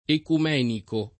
ecumenico [ ekum $ niko ]